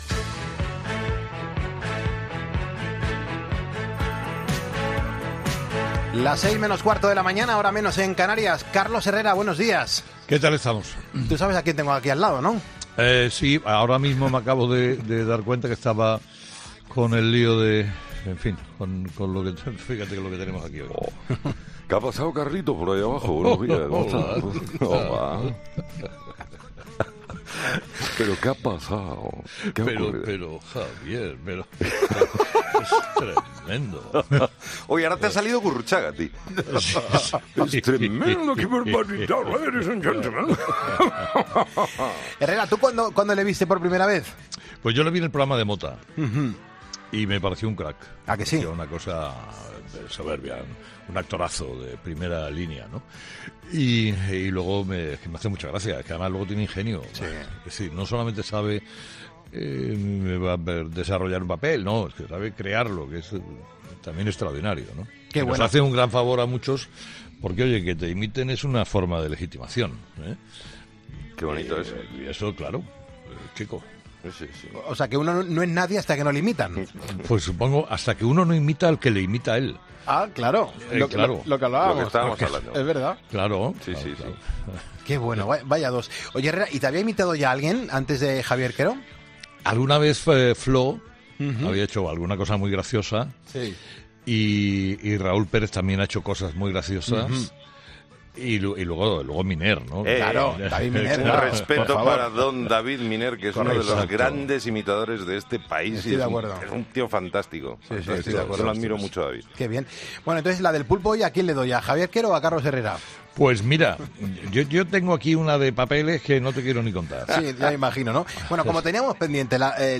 AUDIO: Herrera recibe La Del Pulpo junto al imitador Javier Quero.